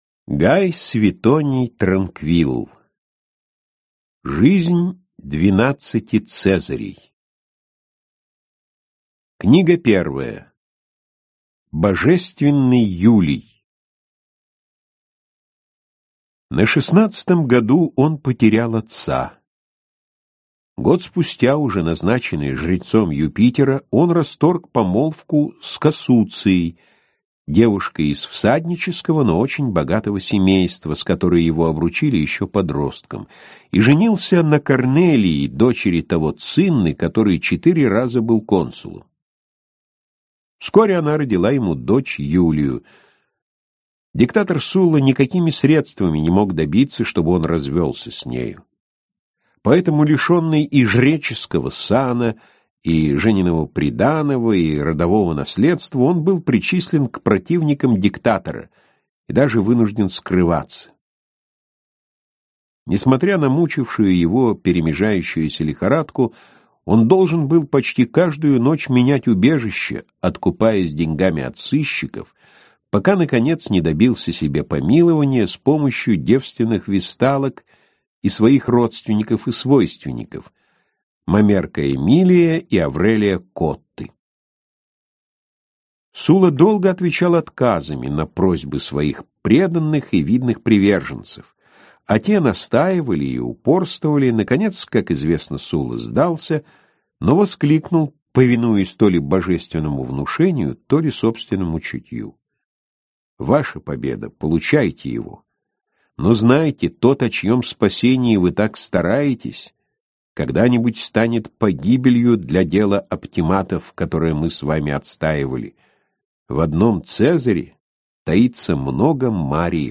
Аудиокнига Жизнь двенадцати цезарей | Библиотека аудиокниг